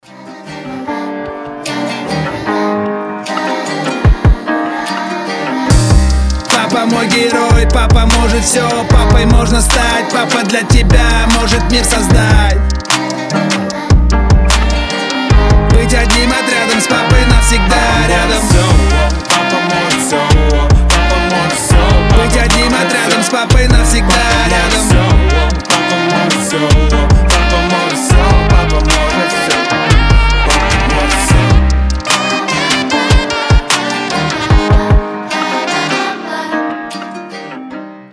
Хип-хоп
душевные
Саксофон
детский голос